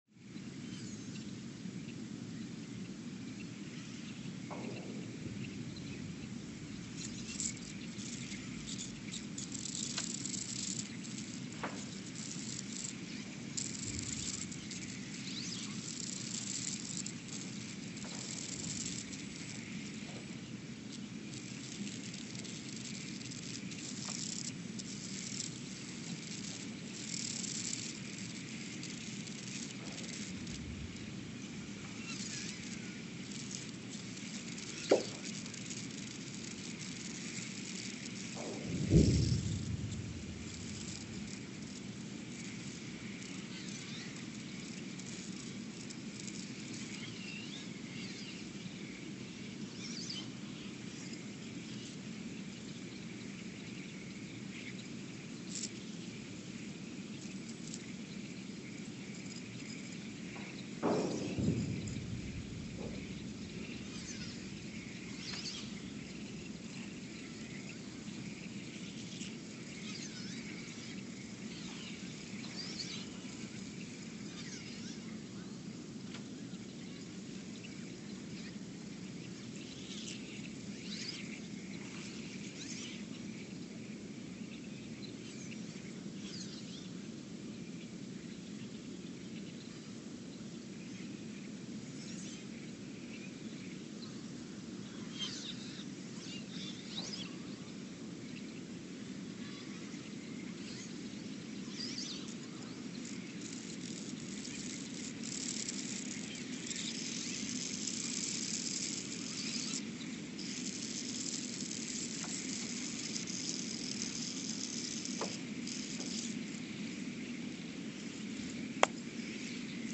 Ulaanbaatar, Mongolia (seismic) archived on September 6, 2023
Sensor : STS-1V/VBB
Speedup : ×900 (transposed up about 10 octaves)
Loop duration (audio) : 03:12 (stereo)
Gain correction : 25dB